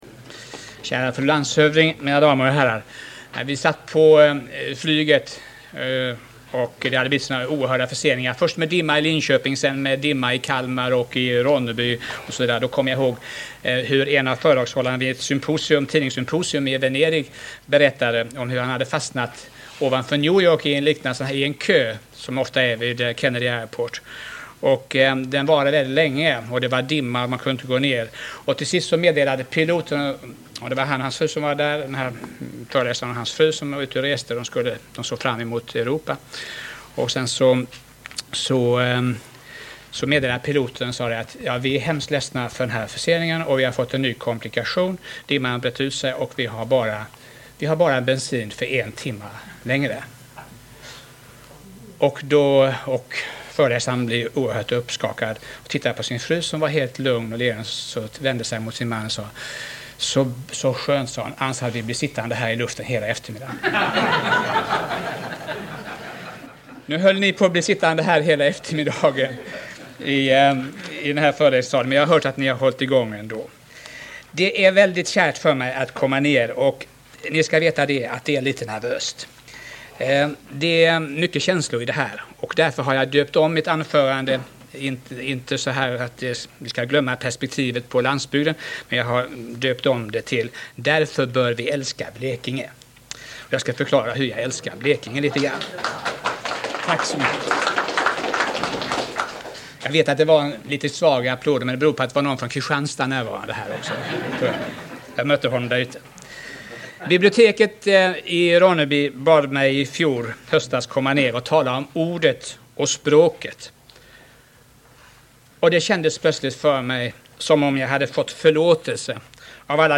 Bertil Torekull håller föredrag i Ronneby den 5 oktober 1988.